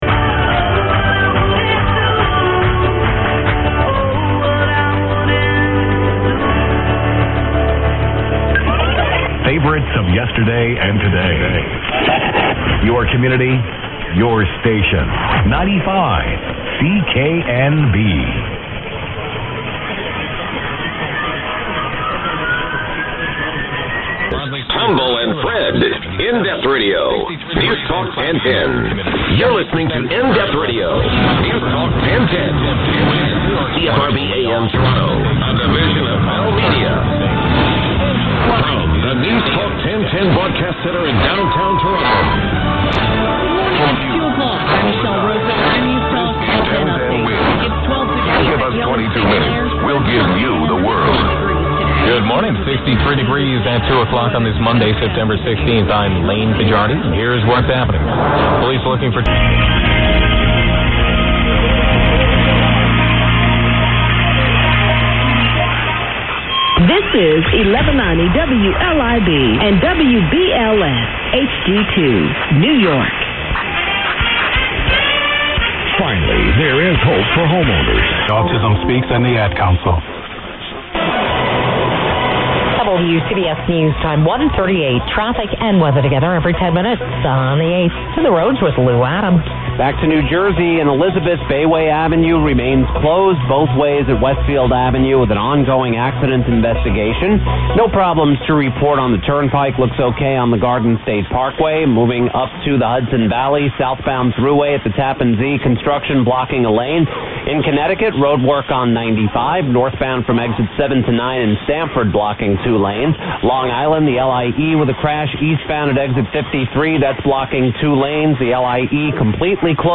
MONTAGE OF COMMON STATIONS HEARD IN THE LAST FEW DAYS:
Here is a sample of the strong singals heard here in the last days, mostly 23rd.